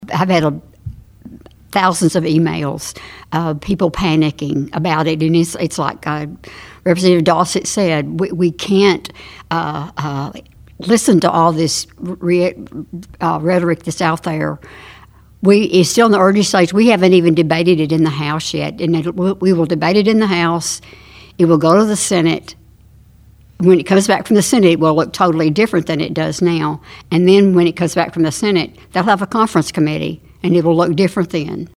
Third District State Senator Craig Richardson was joined by State Representatives Mary Beth Imes, Walker Thomas, and Myron Dossett during the Your News Edge Legislative Update program Saturday that was recorded at the H&R Agri-Power Pancake Day.